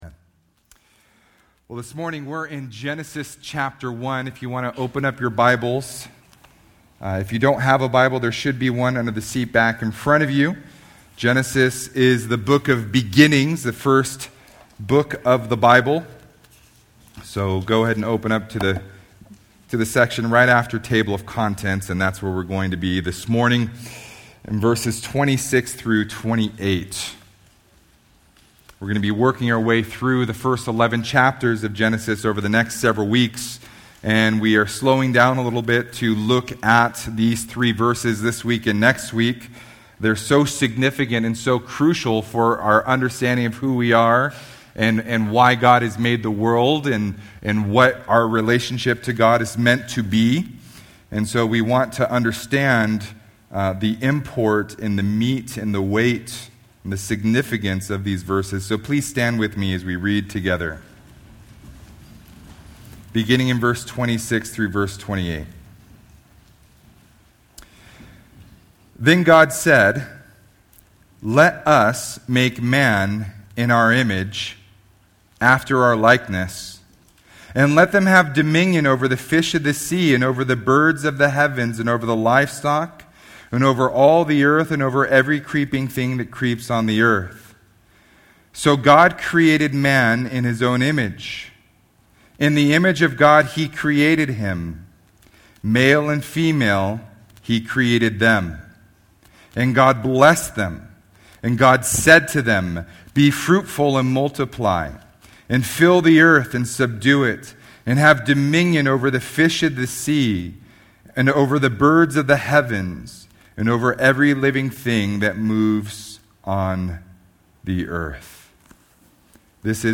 Download Audio Sermon Notes Facebook Tweet Link Share Link Send Email